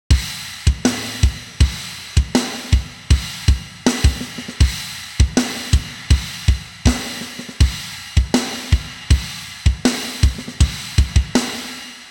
サウンドデモ
ドラム（原音）
SA-3_Drums_Bypassed.wav